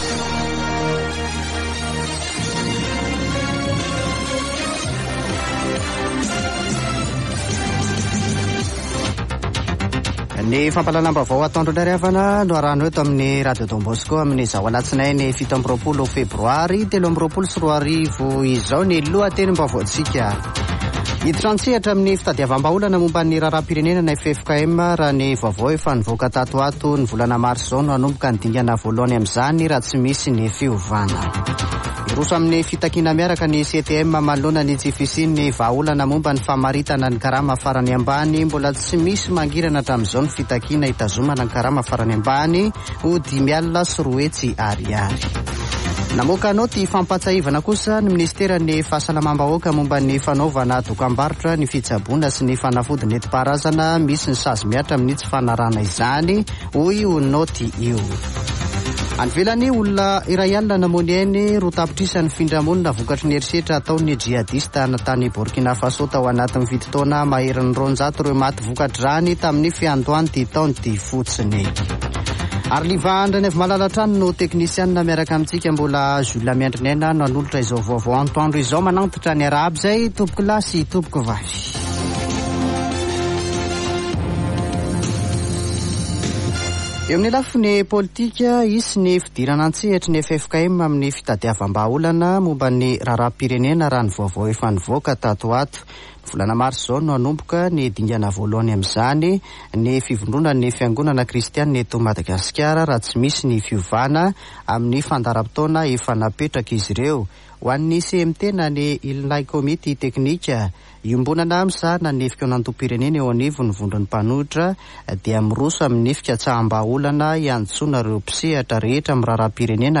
[Vaovao antoandro] Alatsinainy 27 febroary 2023